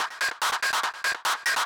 K-6 Loop 2.wav